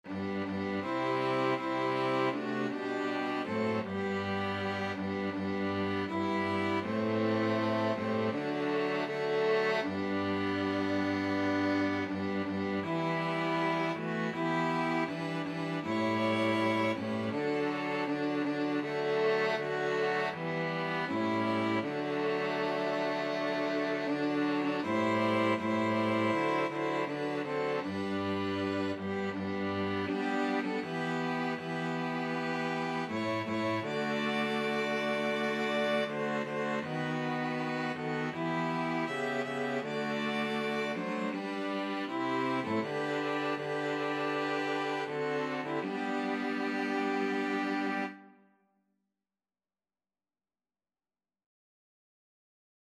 Violin 1Violin 2ViolaCello
4/4 (View more 4/4 Music)
Andante
Christmas (View more Christmas String Quartet Music)